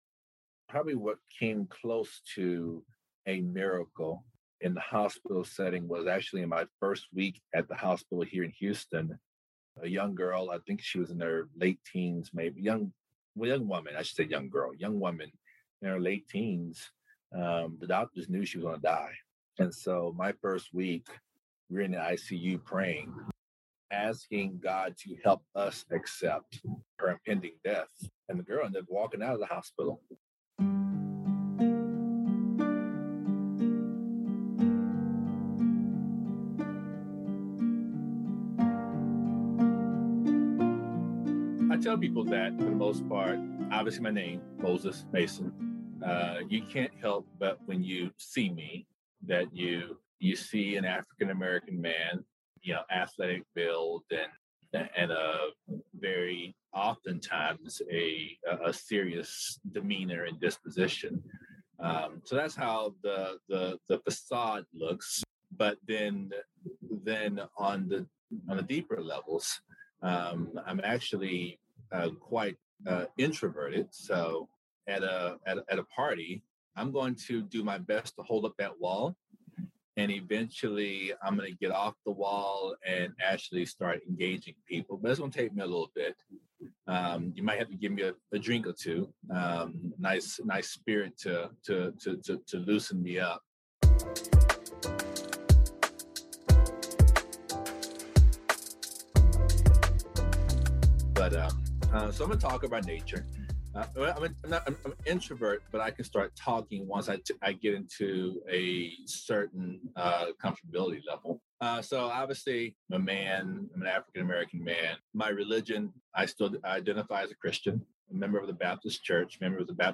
Listen to this longer interview to hear how a former preacher ends up in law school and how what we think is our path is only one stop along the way.